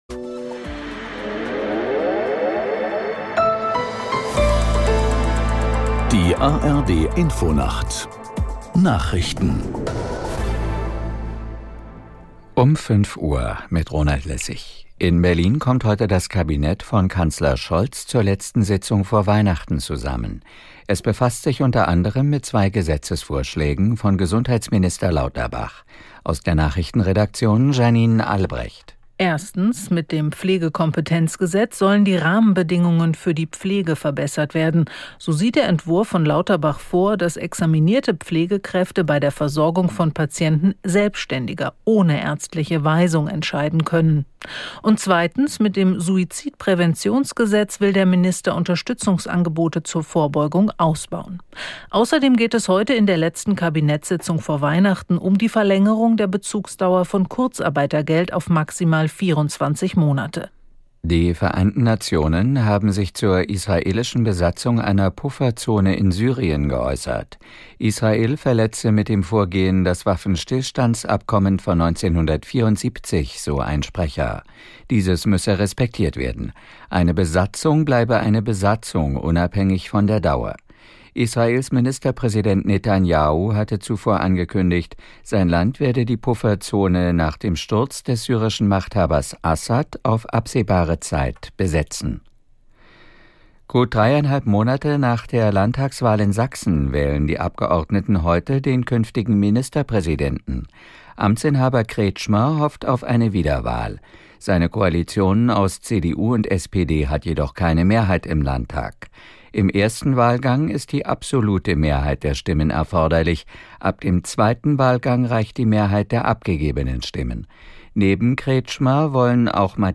Nachrichten für den Norden. Alle 30 Minuten die aktuellen Meldungen aus der NDR Info Nachrichtenredaktion. Politik, Wirtschaft, Sport. 24 Stunden am Tag - 365 Tage im Jahr.